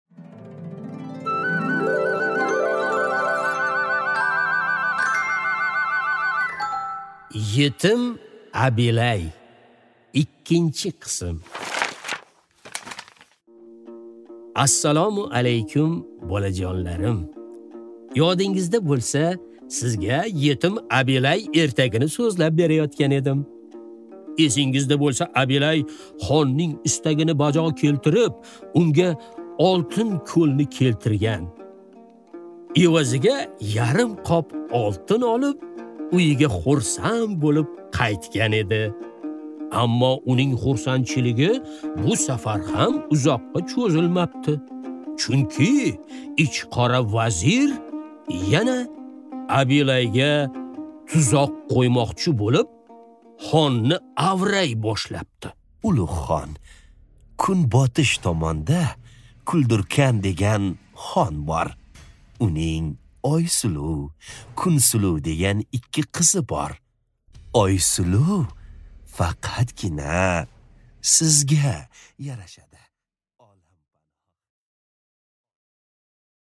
Аудиокнига Yеtim Abilay 2-qism